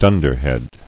[dun·der·head]